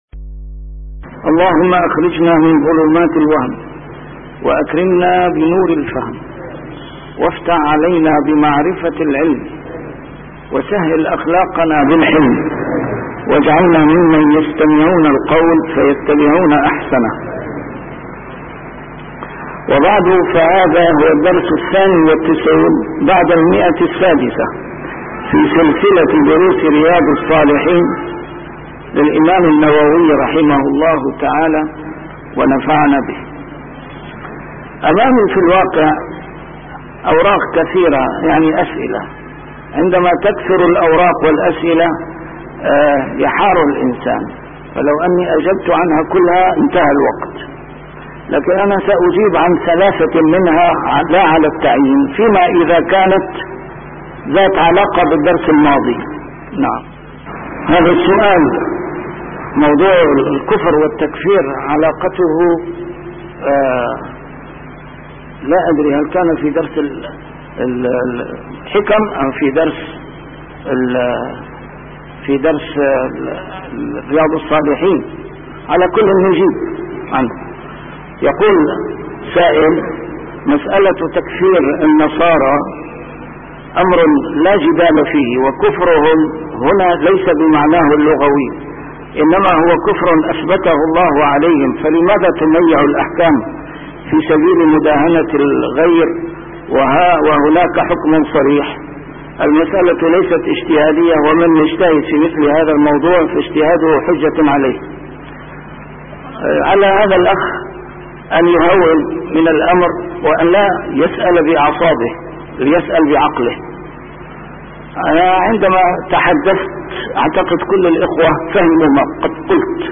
شرح كتاب رياض الصالحين - A MARTYR SCHOLAR: IMAM MUHAMMAD SAEED RAMADAN AL-BOUTI - الدروس العلمية - علوم الحديث الشريف - 692- شرح رياض الصالحين: الاستئذان وآدابه